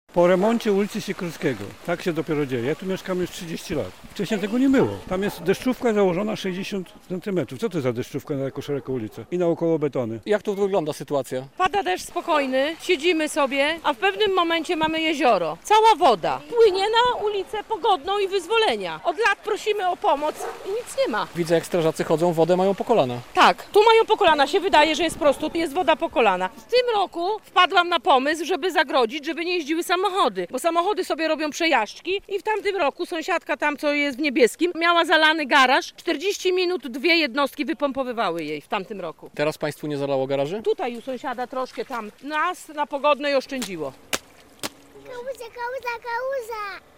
Zalane ulice w Łomży po gwałtownym deszczu - relacja
- Pada deszcz spokojny, siedzimy sobie, a w pewnym momencie mamy jezioro - opowiadała po przejściu nawałnicy jedna z mieszkanek Łomży.